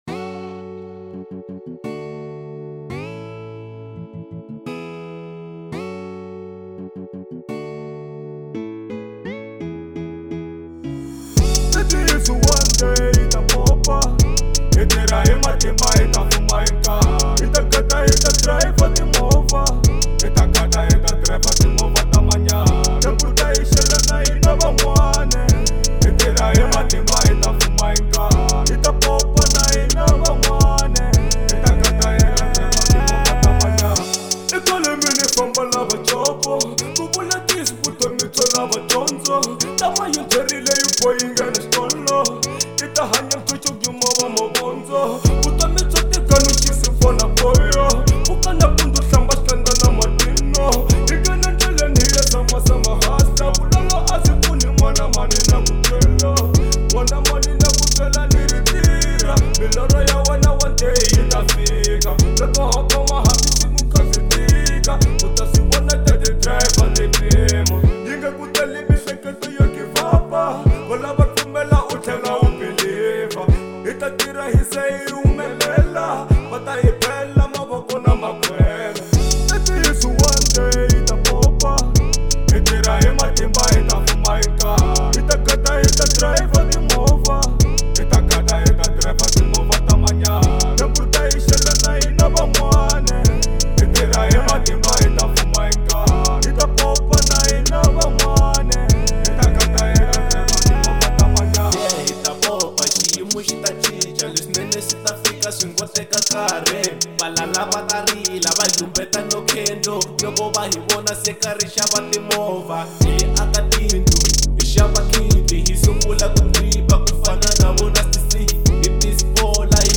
03:18 Genre : Hip Hop Size